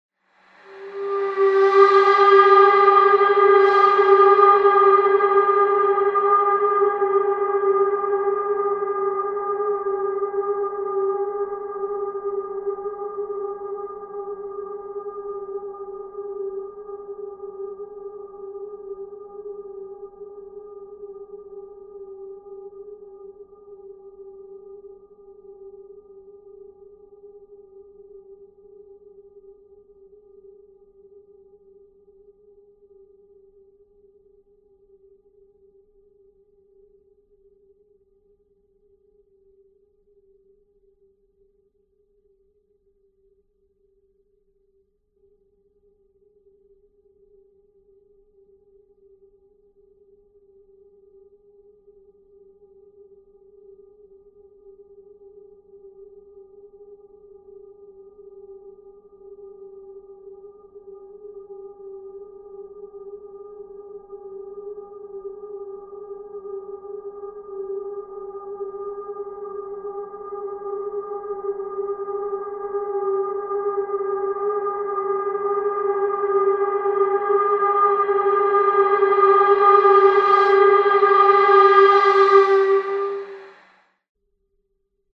Steam whistle through worlds longest reverb
convolution